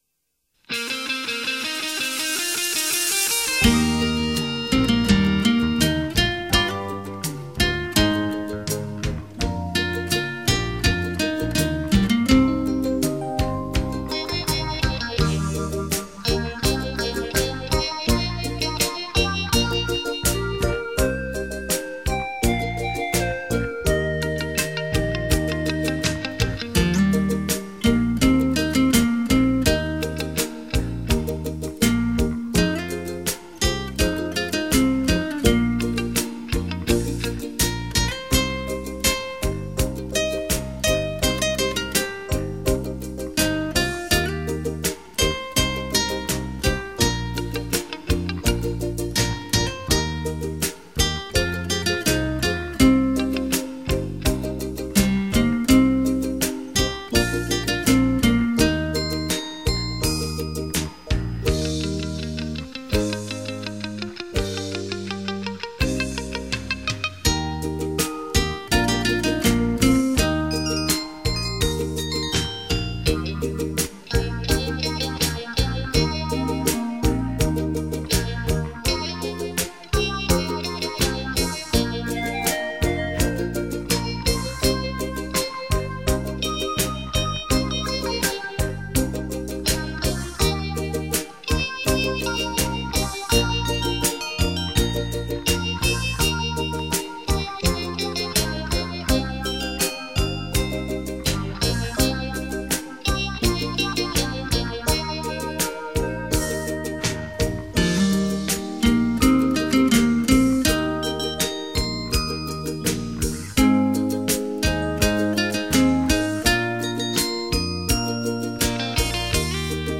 热情浪漫的悠扬音乐